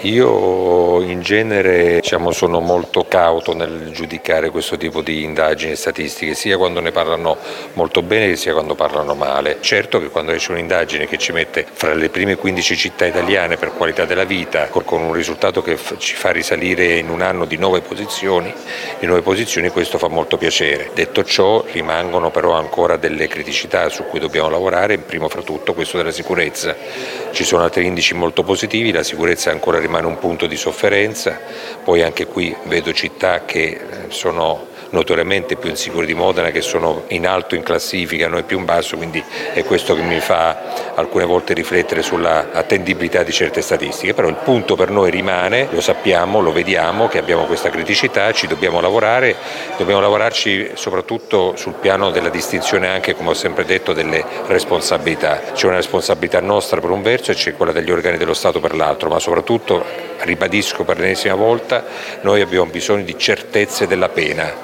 A commentare questi dati è il sindaco di Modena Massimo Mezzetti